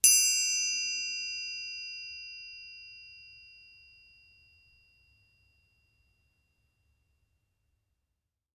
Triangle Sml Strike Still